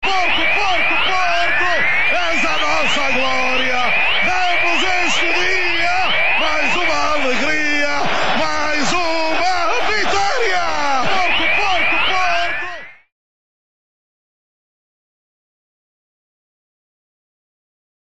«Mais uma alegria, mais uma vitória», canta Jorge Perestrelo.
Aos 75 minutos, Alenichev marca o terceiro golo dos dragões frente ao Mónaco, na final da Liga dos Campeões. O Futebol Clube do Porto de José Mourinho vence a competição, um triunfo narrado com emoção por Jorge Perestrelo.